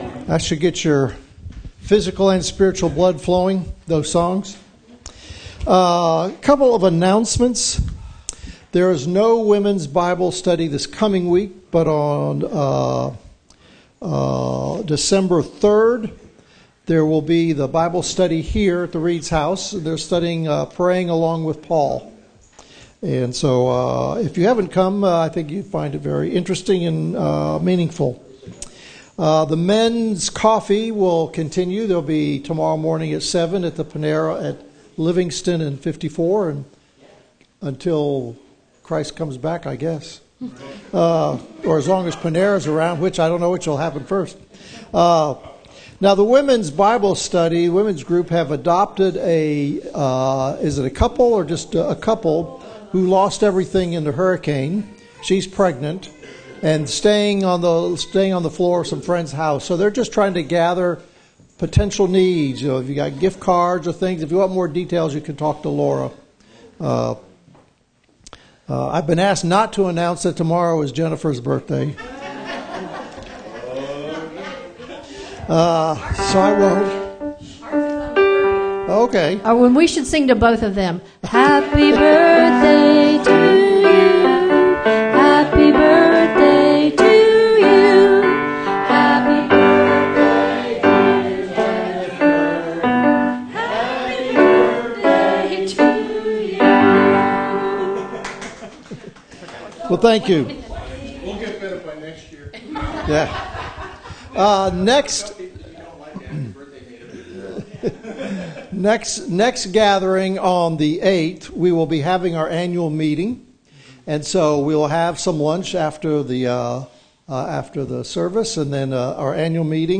Service Type: Gathering